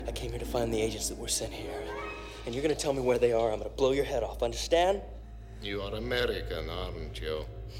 Memorable Dialog